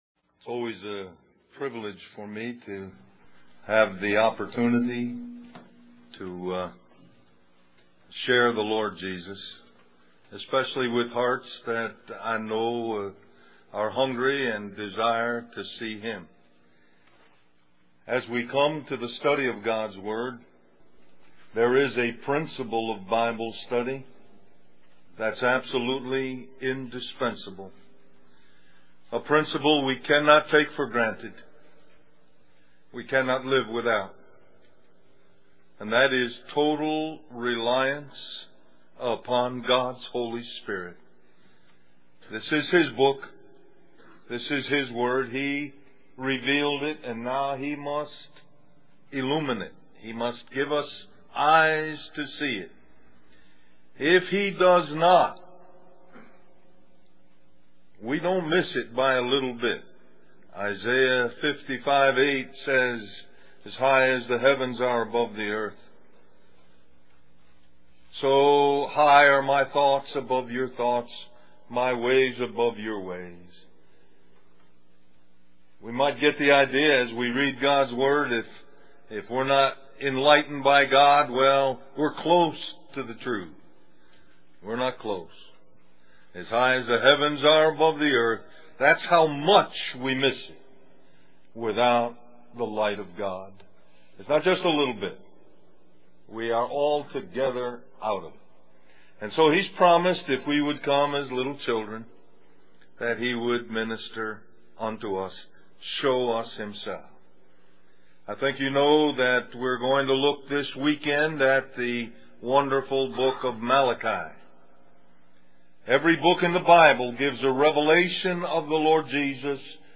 A collection of Christ focused messages published by the Christian Testimony Ministry in Richmond, VA.
Del-Mar-Va Men's Retreat